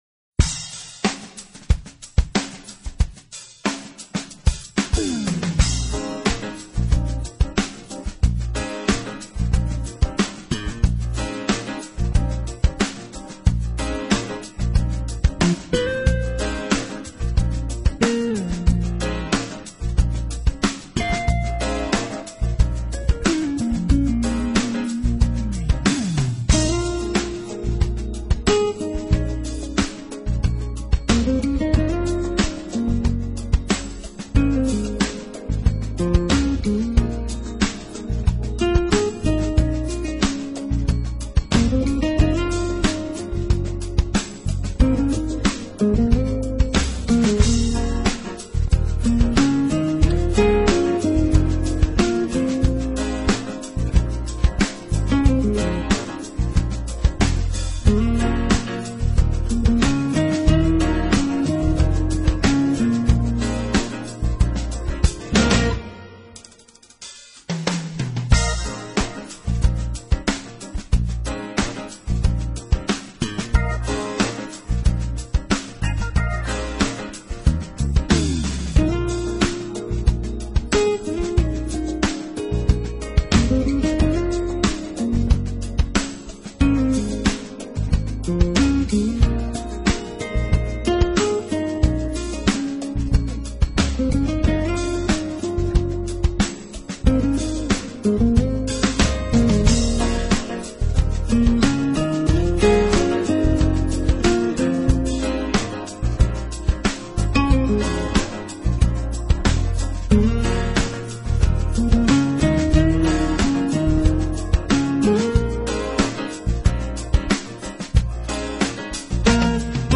音乐类型：Jazz 爵士
音乐风格：Contemporary,Instrumental,Newage